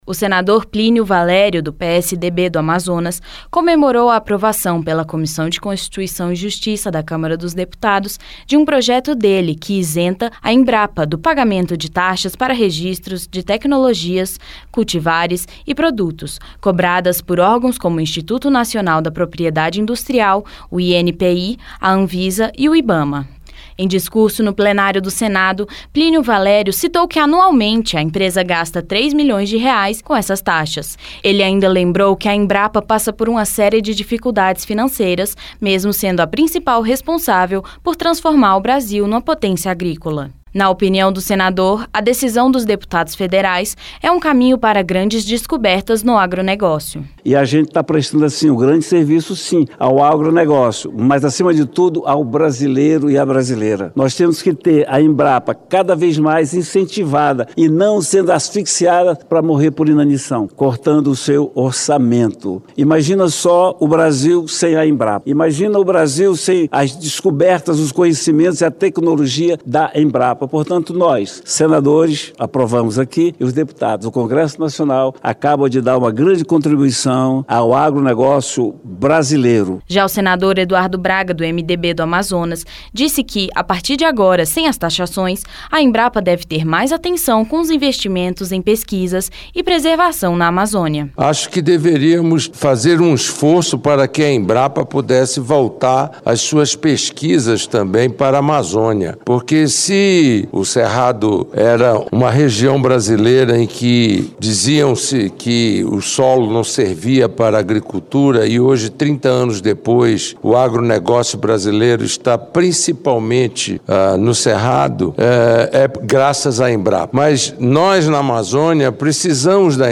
Senador Eduardo Braga
Senador Plínio Valério